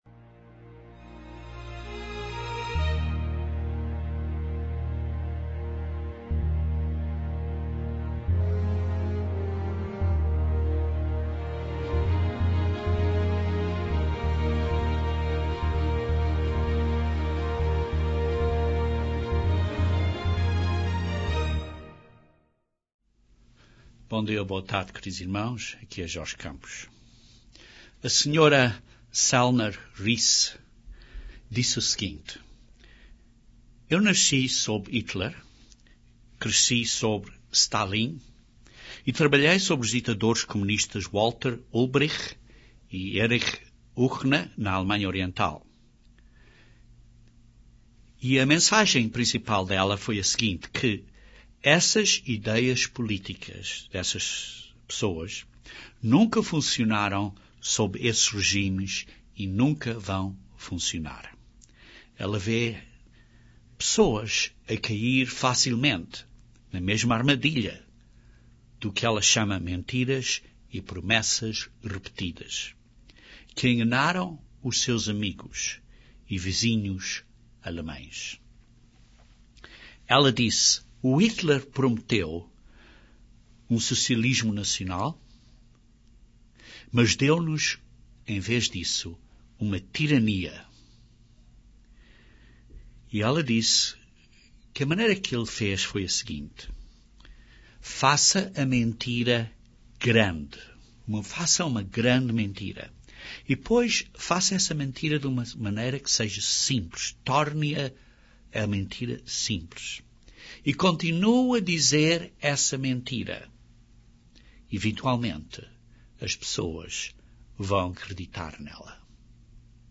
Este sermão aborda este assunto: A cobiça pela liberdade.